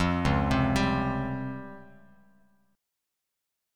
C#M#11 chord